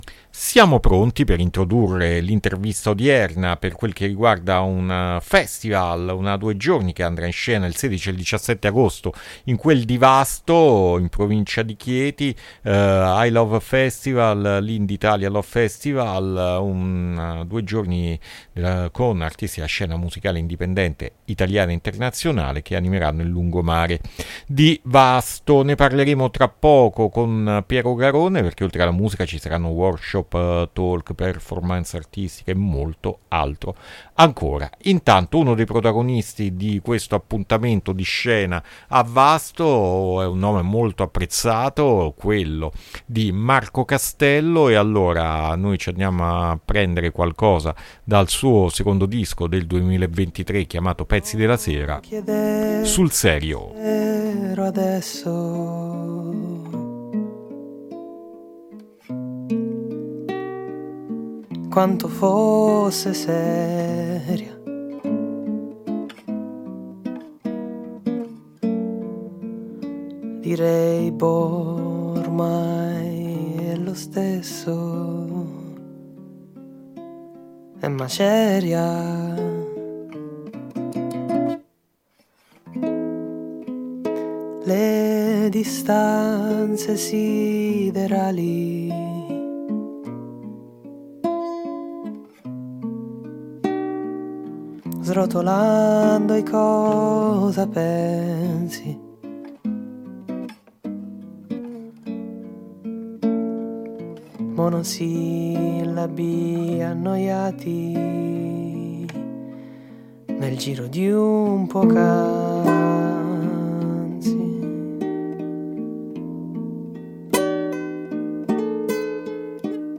INTERVISTA ILOVEFESTIVAL AD ALTERNITALIA 8-8-2025